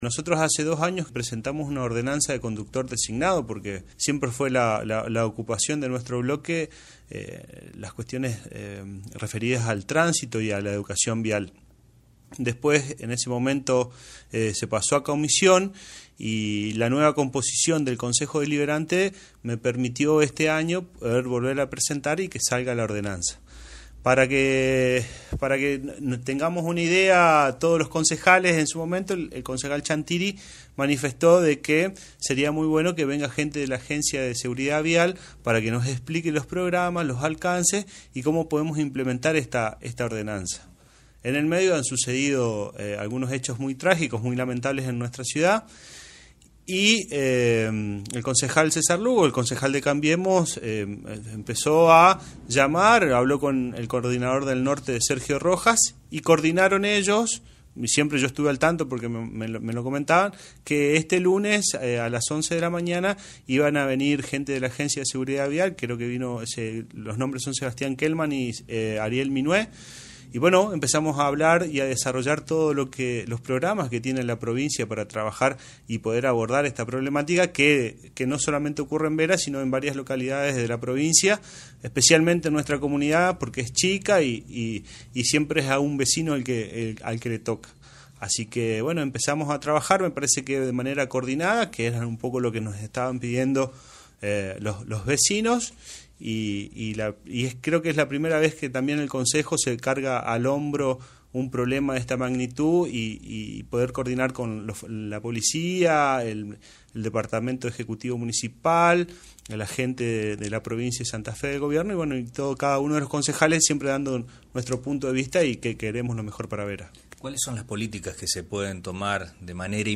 AUDIO: Concejal Alejandro Bustos